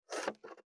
492切る,包丁,厨房,台所,野菜切る,咀嚼音,ナイフ,調理音,まな板の上,料理,
効果音